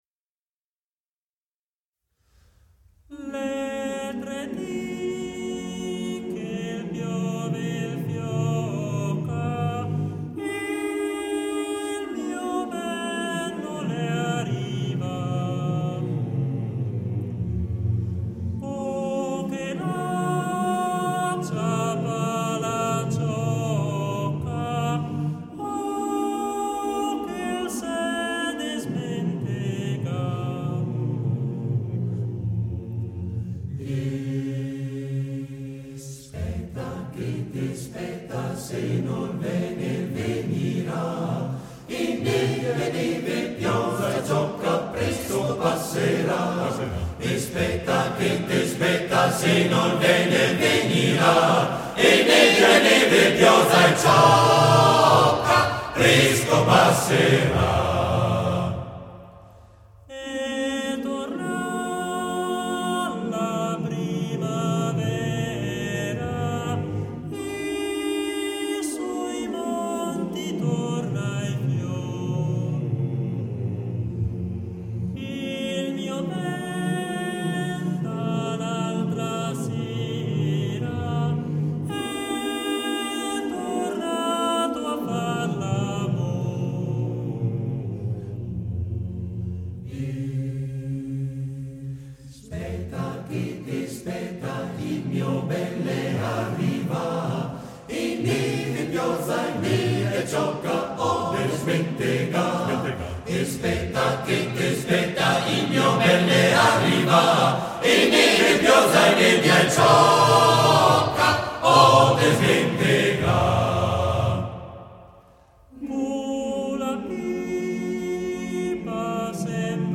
Patrimoni musicali della cultura alpina
Esecutore: Coro della SAT